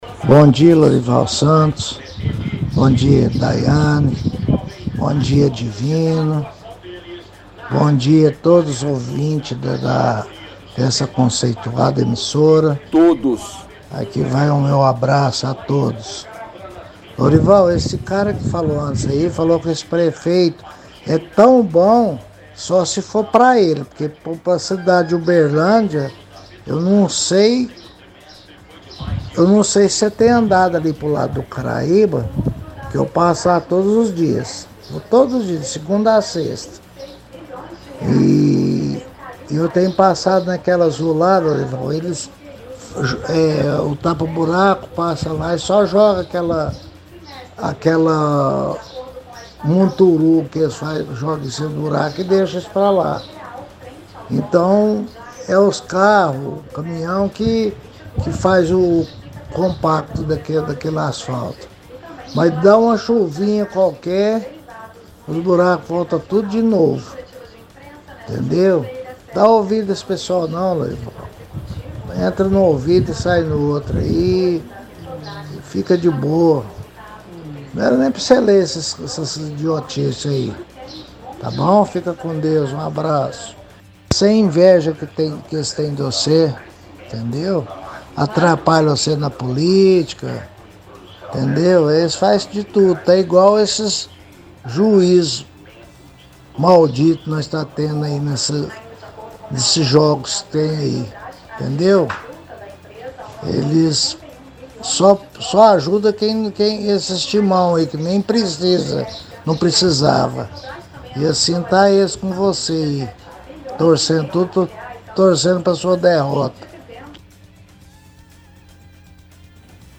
– Ouvinte reclama das condições das vias no bairro Karaiba e da qualidade do serviço de recapeamento, que é mal feito e se desfaz com a chuva.